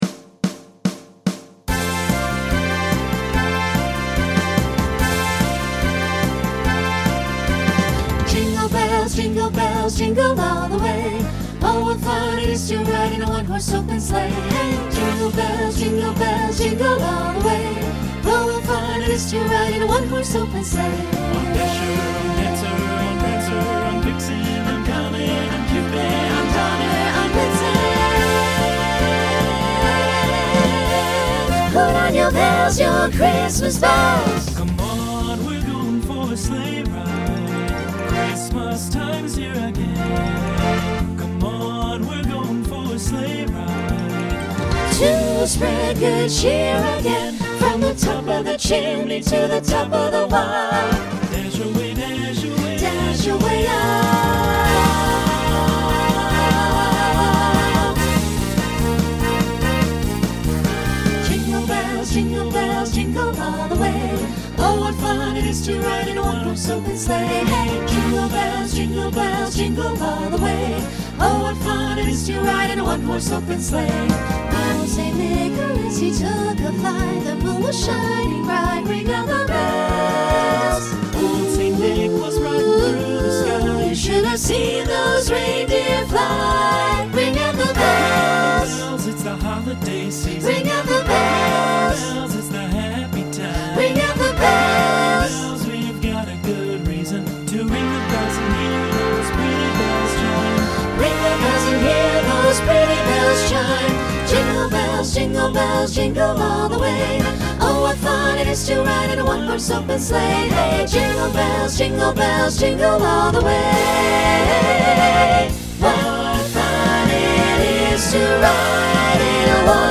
Holiday , Rock , Swing/Jazz
Opener Voicing SATB